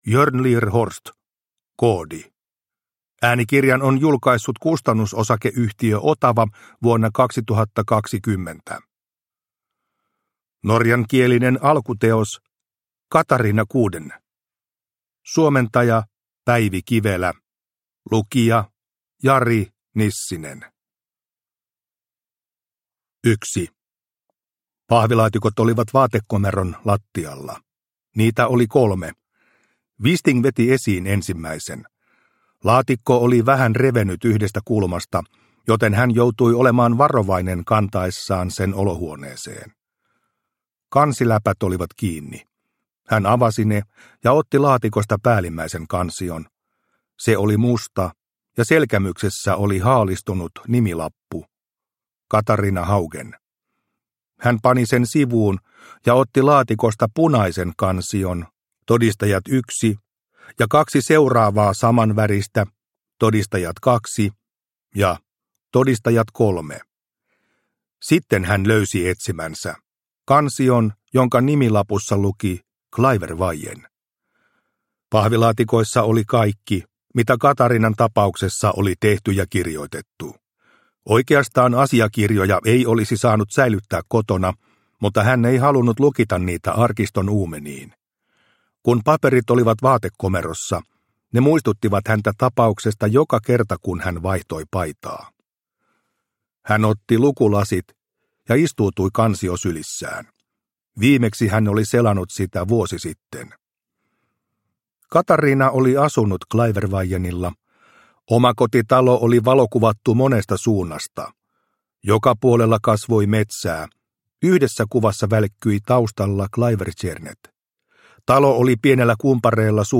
Koodi – Ljudbok – Laddas ner